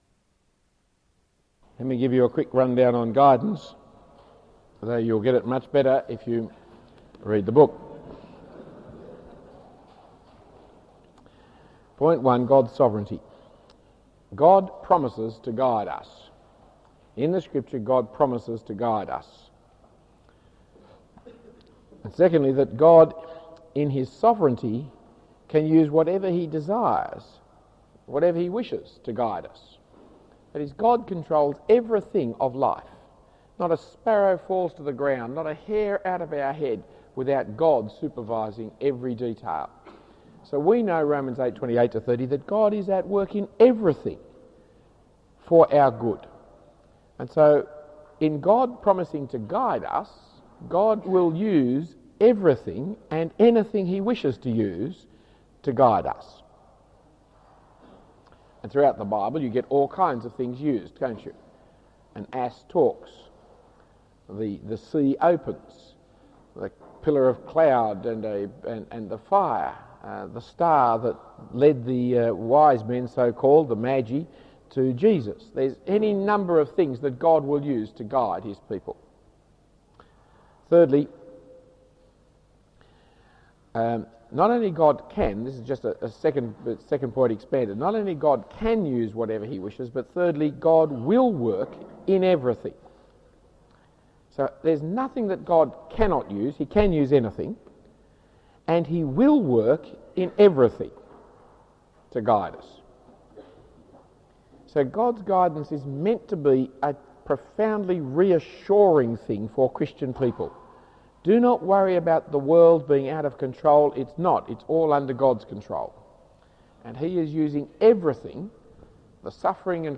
Talk 5 of 5 in the series Mid Year Conference 1997 Wisdom given at Katoomba for the University of New South Wales.